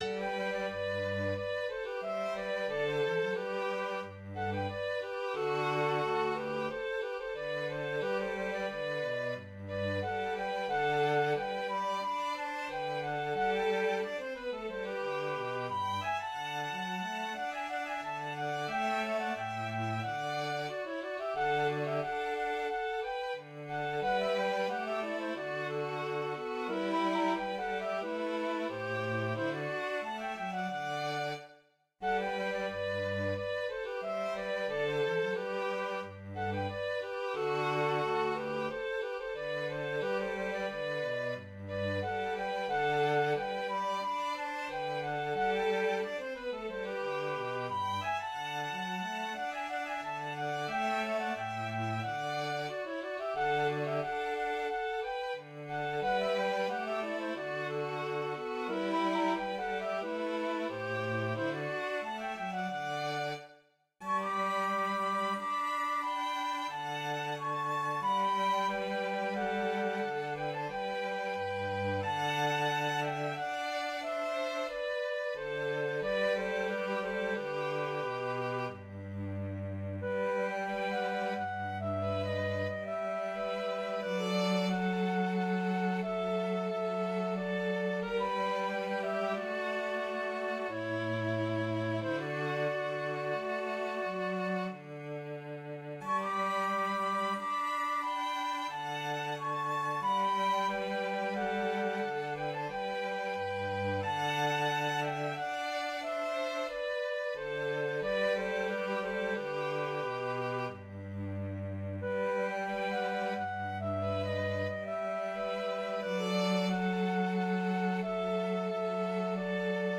Here's an example of a Musicalische Cabala minuet-trio.
algorithmic-composition computer-music mdg computer-generated-music musical-dice-game musikalisches-wurfelspiel minuets aleatoric-music trios minuet-trios franciscus-schola musicalische-cabala